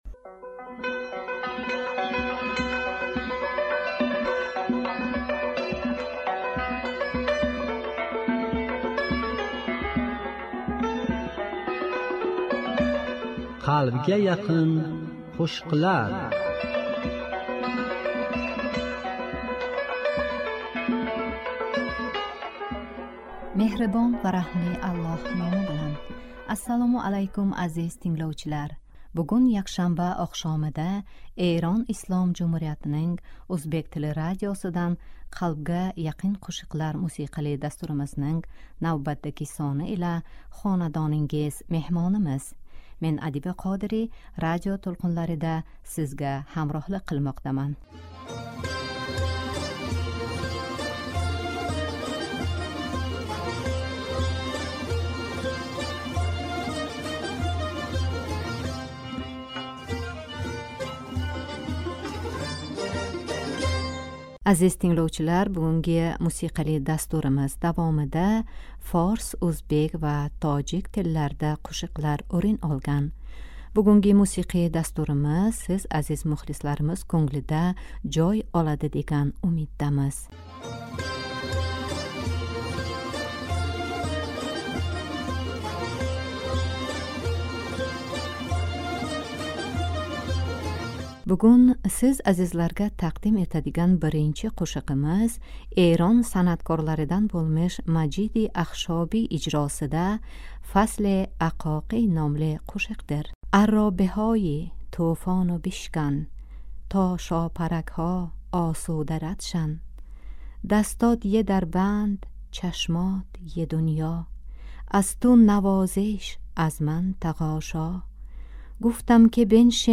Мусиқа Азиз тингловчилар, бугунги мусиқали дастуримиз давомида форс, ўзбек ва тожик тилларда қўшиқлар ўрин олган.